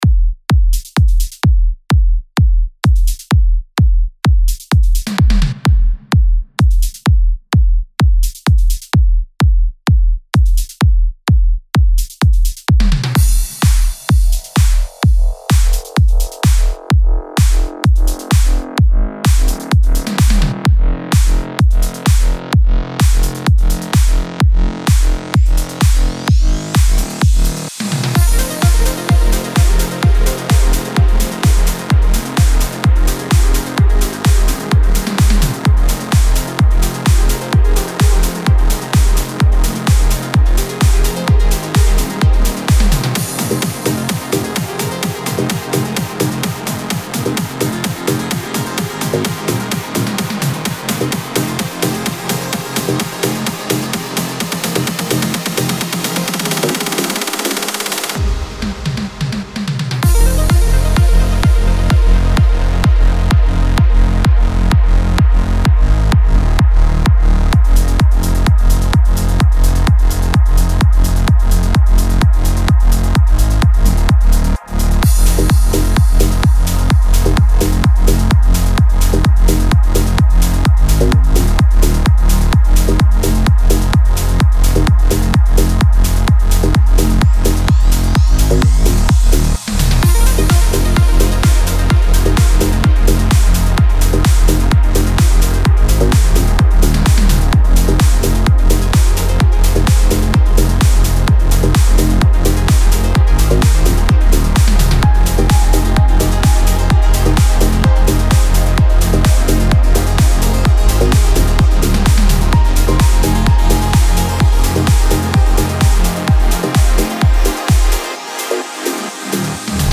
• 1 x Complete Production Progressive Trance Track.
• BPM – 128.
• Song Key – Fm or F minor.